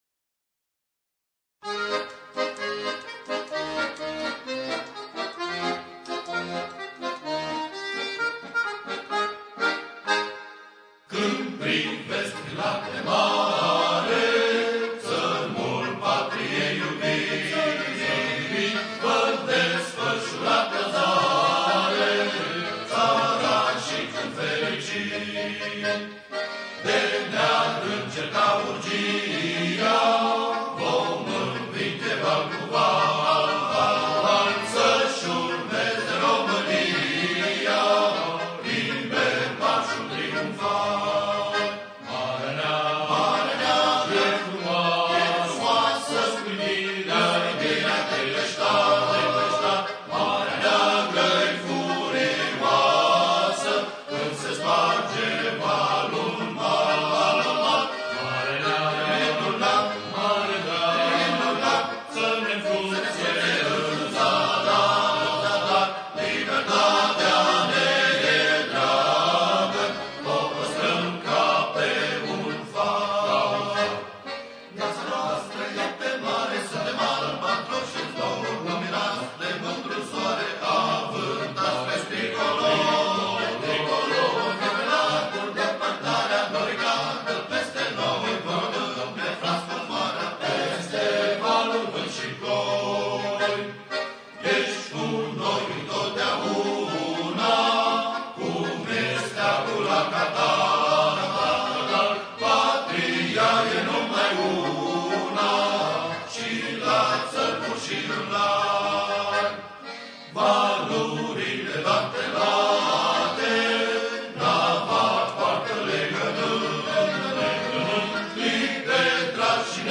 Coruri din repertoriul marinăresc
marș